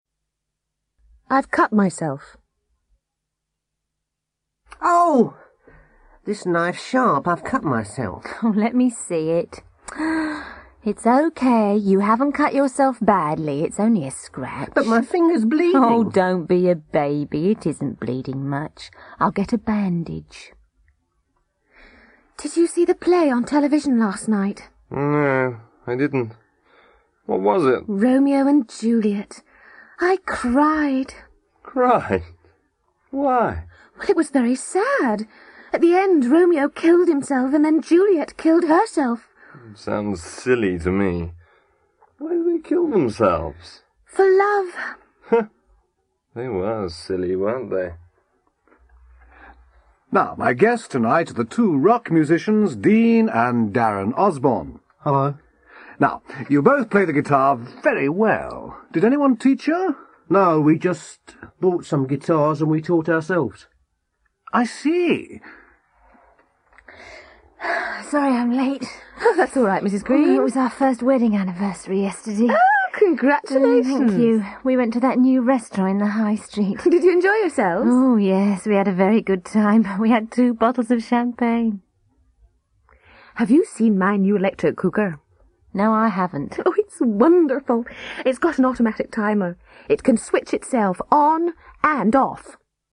English conversation